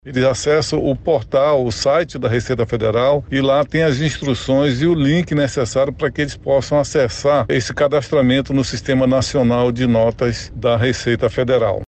O gestor explica como os microempreendedores Individuais podem realizar o cadastro na plataforma nacional.